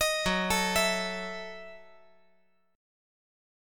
F#m13 Chord
Listen to F#m13 strummed